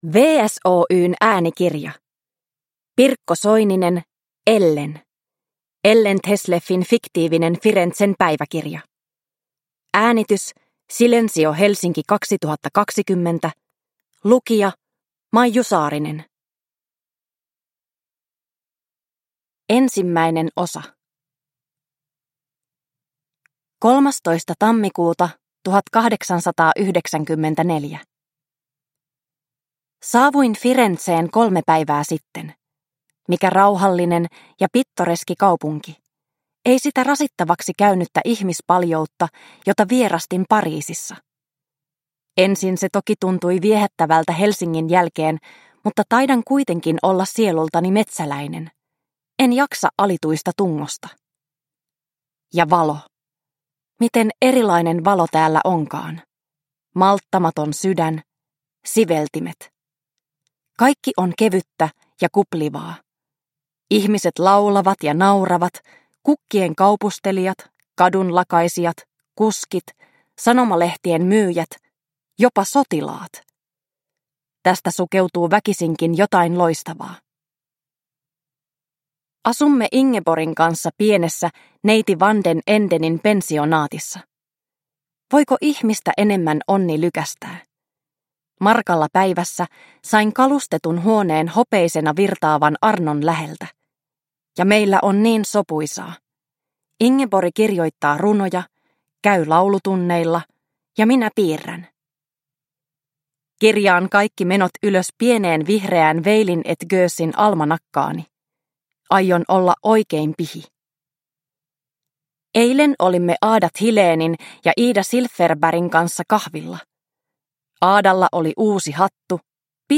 Ellen – Ljudbok – Laddas ner